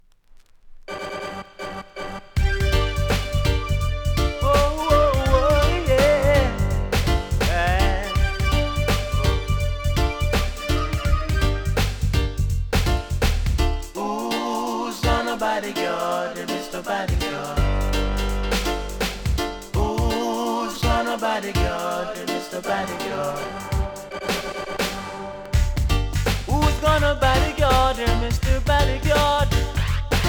REGGAE 80'S
ガッシー・サウンドに乗せてセルフ・リメイク♪
多少うすキズありますが音には影響せず良好です。